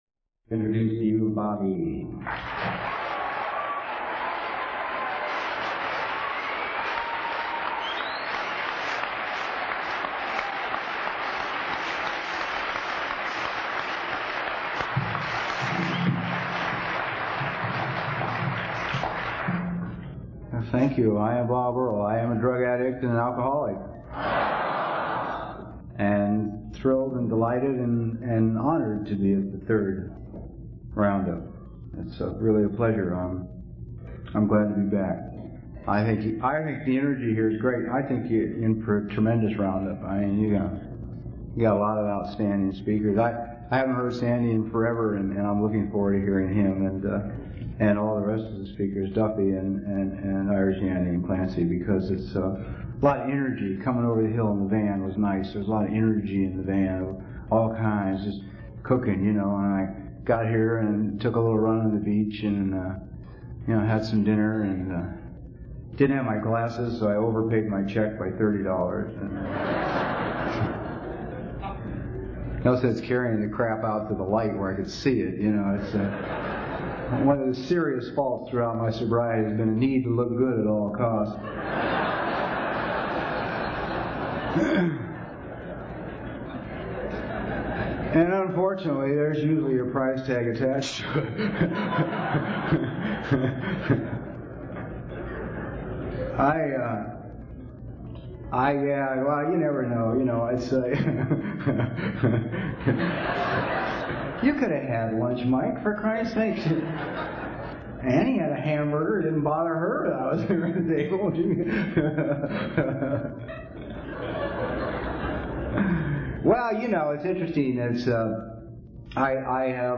Seaside Oregon 1985
AA Speaker Audios